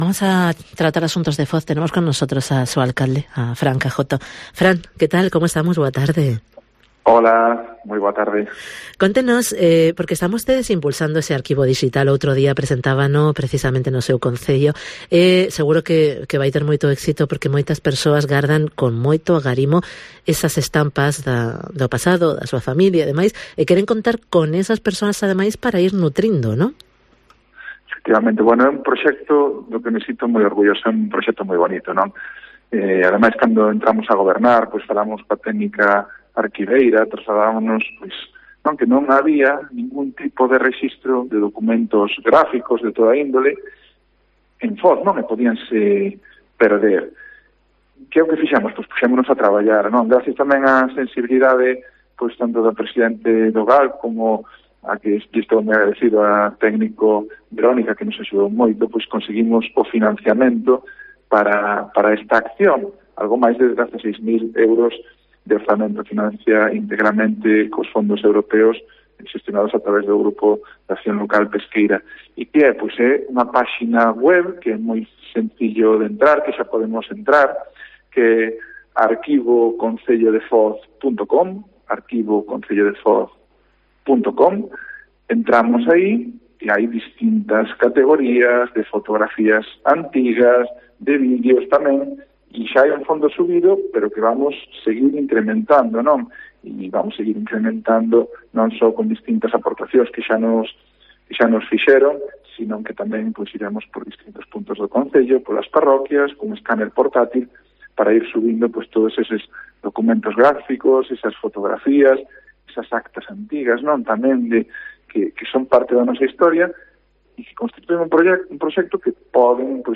Entrevista con Fran Cajoto, alcalde de Foz